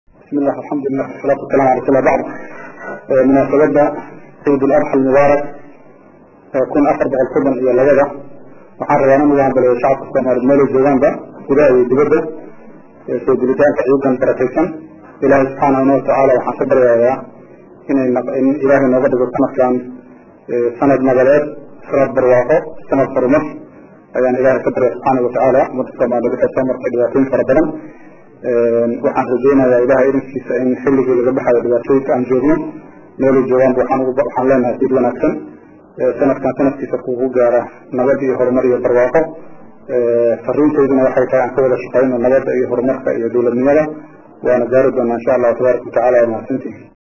Kalmadii Ciida ee Madaxwayne Sheekh Shariif ee Maanta u jeediyay shacabka Soomaaliyeed
Kalmadii Ciida ee Madaxwayne Sheekh Shariif 2011.mp3